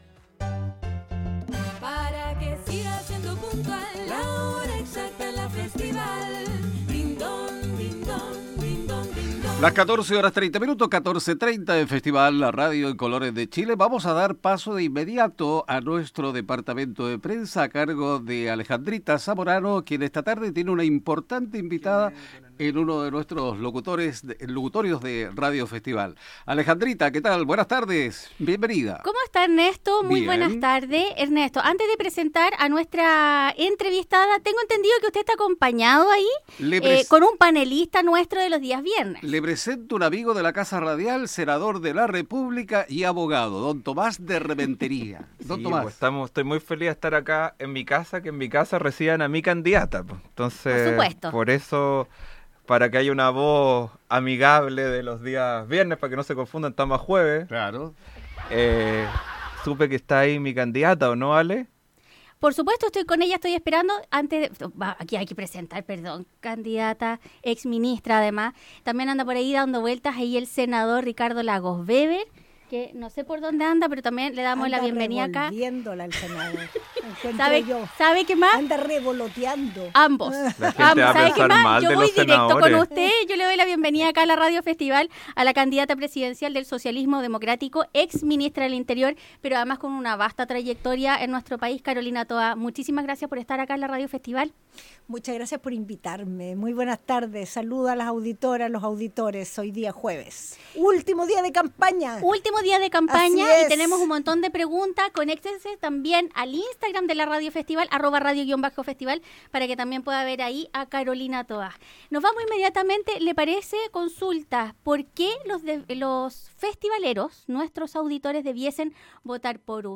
Carolina Toha en los estudios de Radio Festival